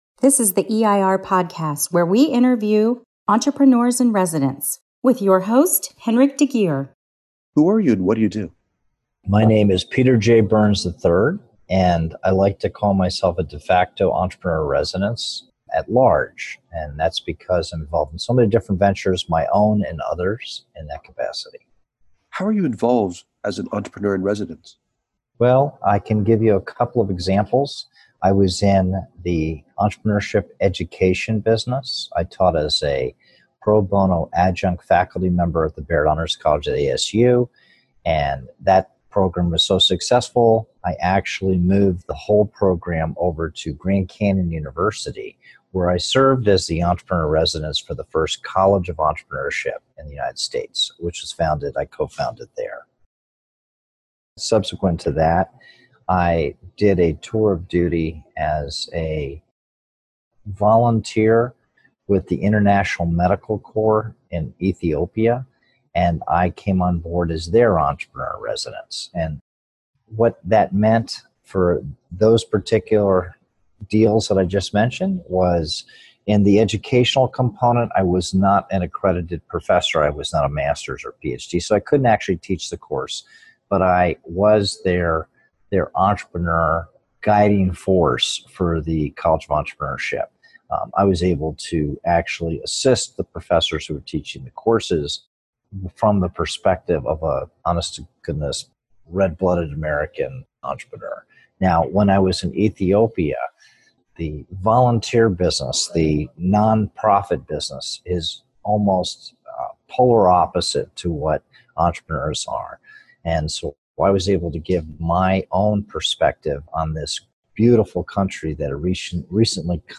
Here is an audio interview